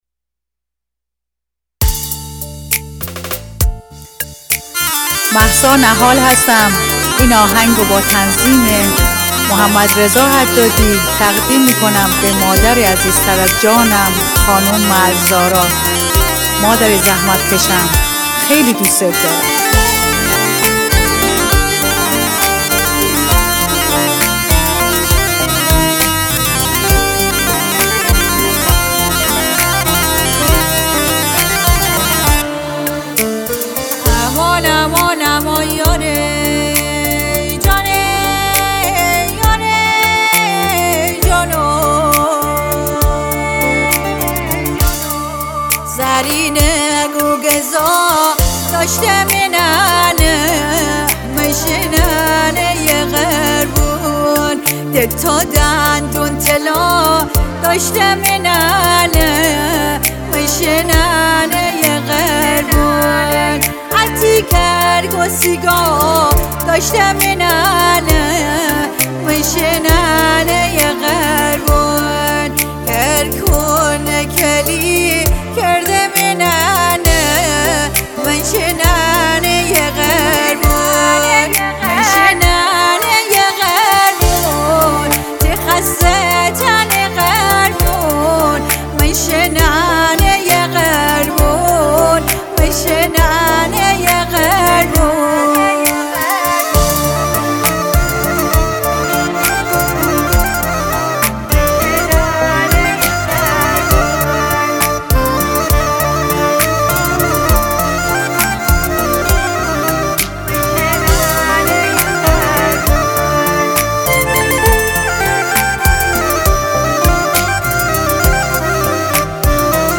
غمگین
آهنگ غمگین مازندرانی